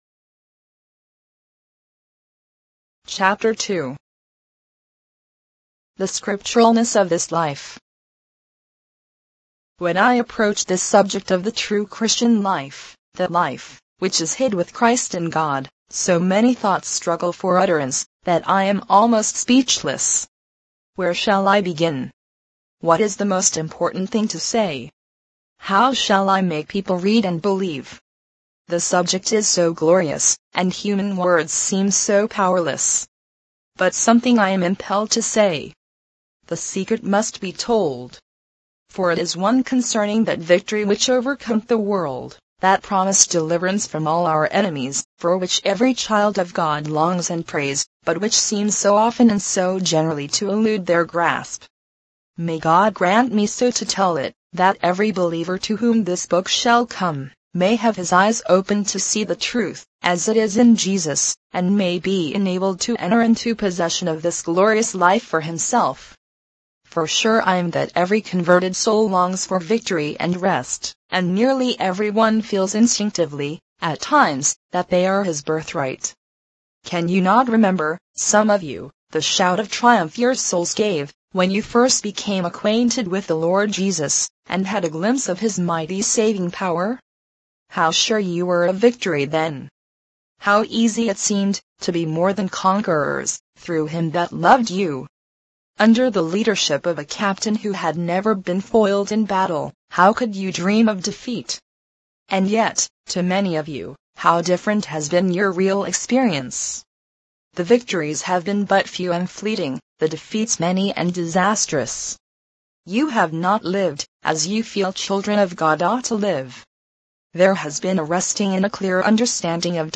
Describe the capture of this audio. The Christian's Secret of a Happy Life in by Hannah W. Smith in mp3 audio format - 32kbps mono